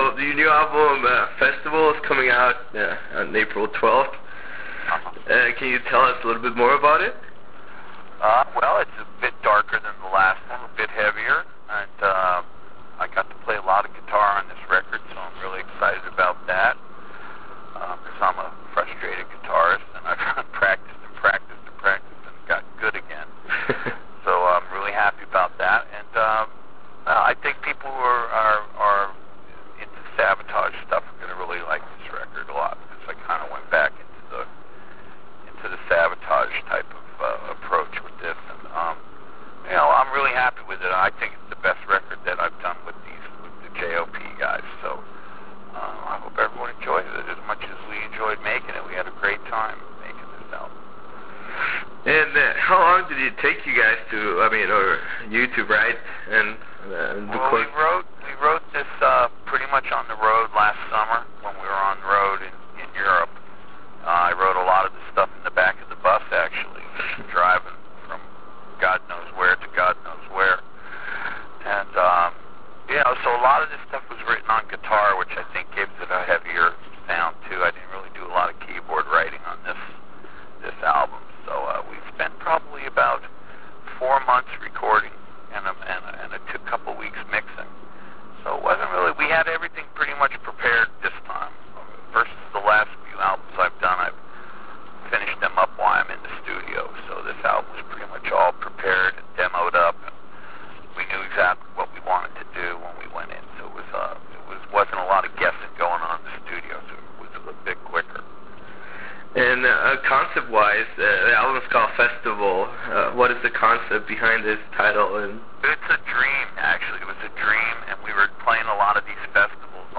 Interview with Jon Oliva's Pain - Jon Oliva
In the eve of the American release of Jon Oliva’s Pain latest masterpiece “Festival”, we managed to sneak a conversation with none other than Jon Oliva himself. In the 20+ minutes interview we talk about the process behind the release of the album. We also talk about making music in this modern age and the traditional questions about Savatage.